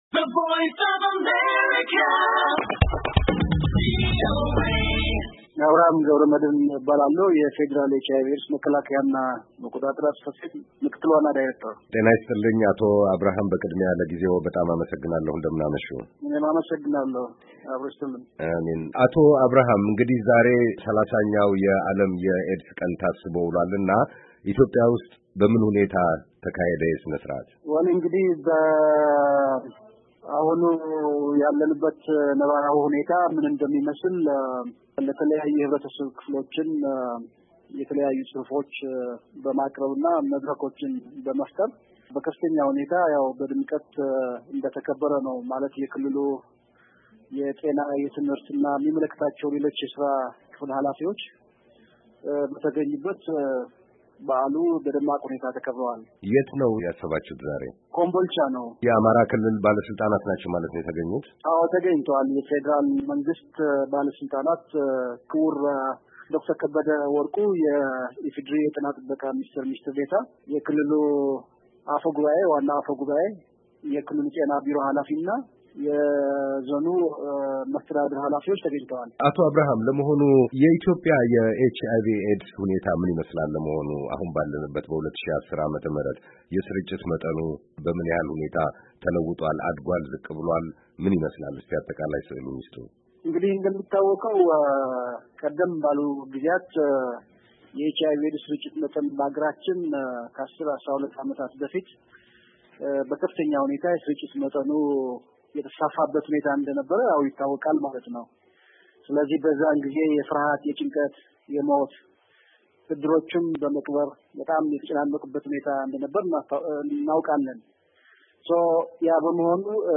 ቃለ-ምልልስ